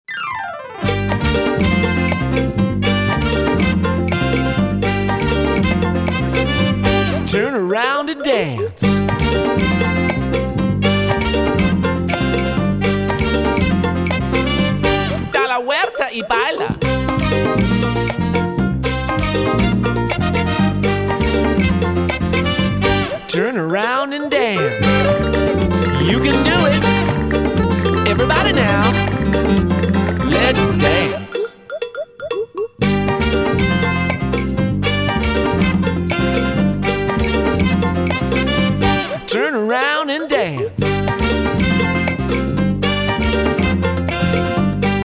movement songs